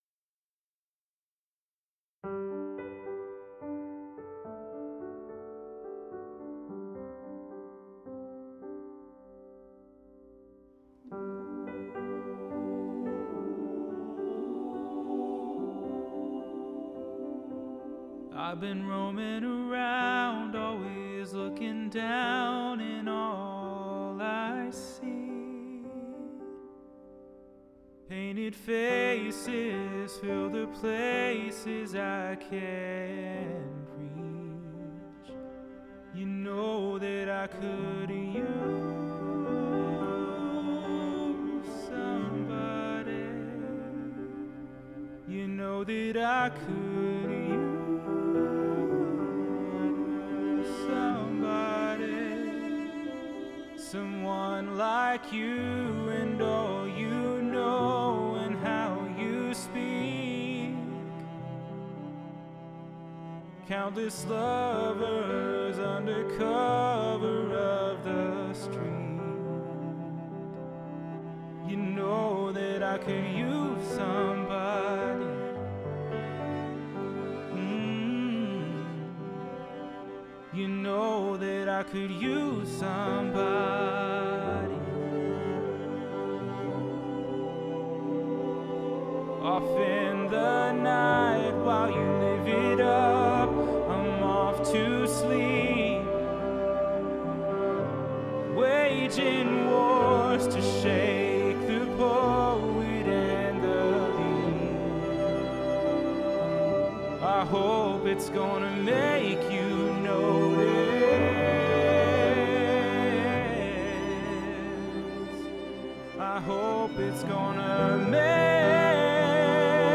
Again, thanks to the strict rules in place for Covid, all parts were recorded separately.